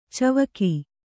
♪ cavaki